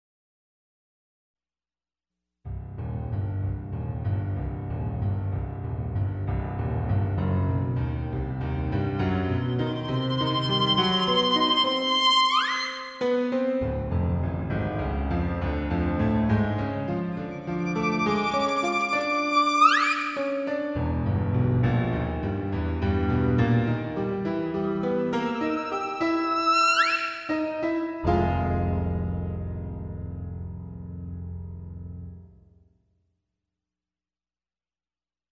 a Hitchock-style theme I did on the piano
violin, to create suspense.